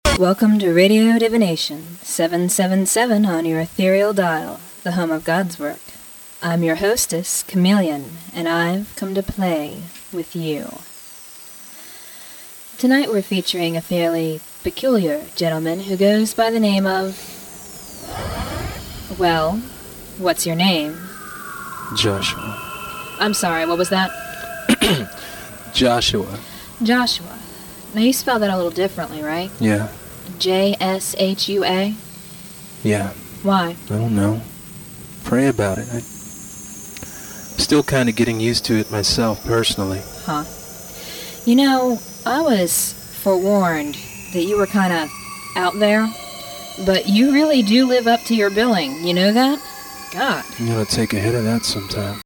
All Instruments & Vocals
Turntablism & Scratches
Raps
Vocals